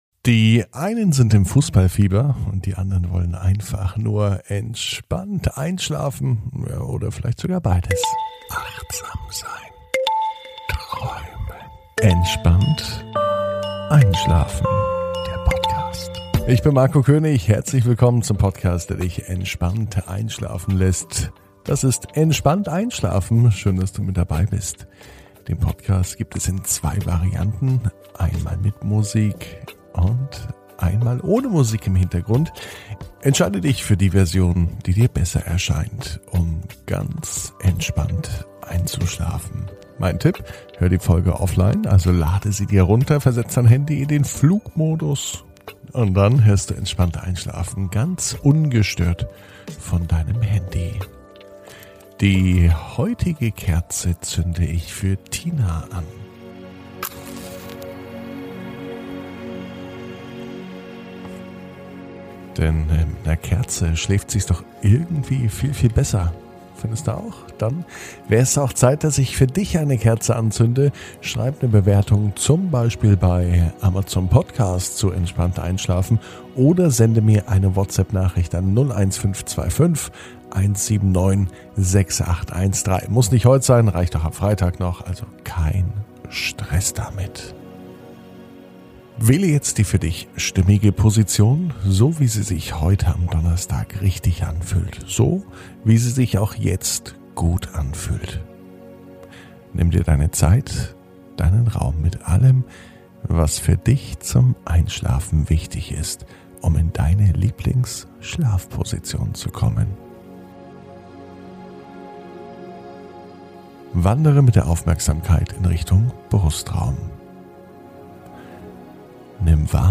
Entspannt einschlafen am Donnerstag, 17.06.21 ~ Entspannt einschlafen - Meditation & Achtsamkeit für die Nacht Podcast